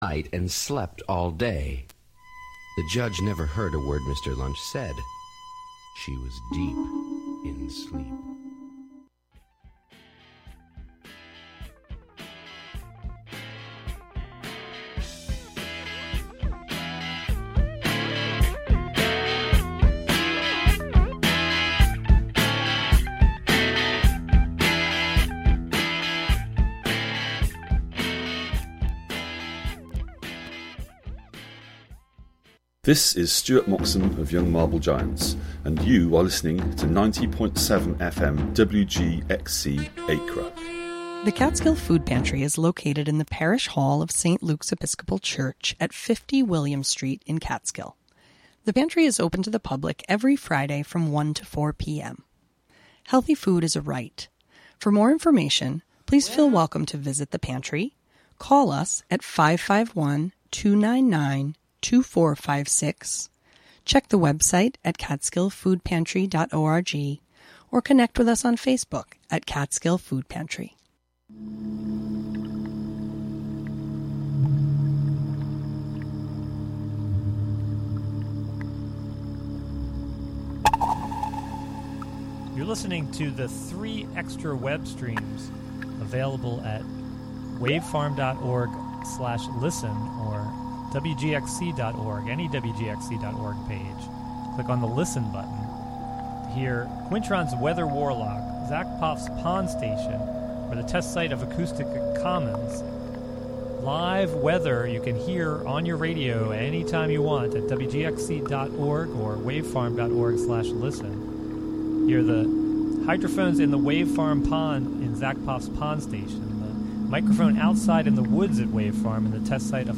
The show honors the necessity to centralize music and sound in decolonizing and indigenizing culture. It also amplifies Indigenous music traditions to bring attention to their right to a sustainable future in the face of continued violence and oppression.